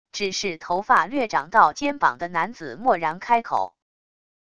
只是头发略长到肩膀的男子漠然开口wav音频生成系统WAV Audio Player